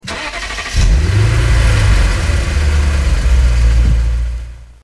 Home gmod sound vehicles tdmcars lex_isf
enginestart.mp3